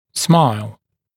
[smaɪl][смайл]улыбка; улыбаться